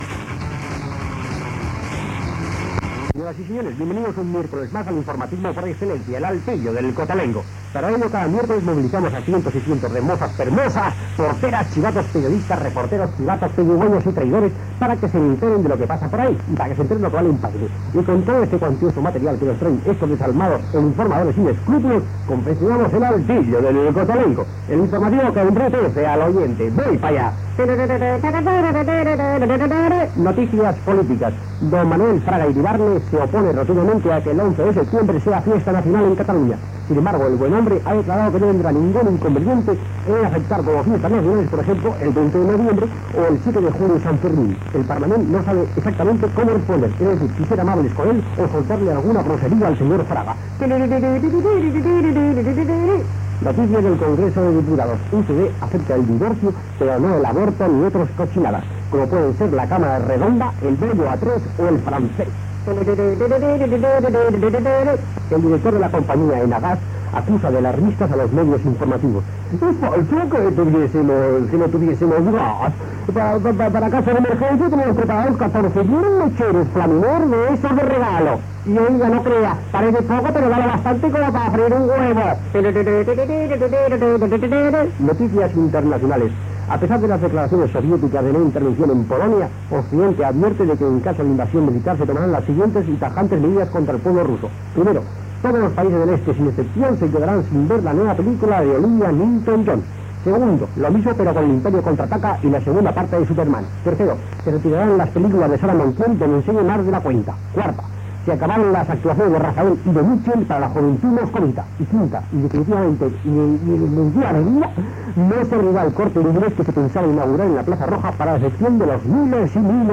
"El altillo del cotolengo", informatiu humorístic
Entreteniment
FM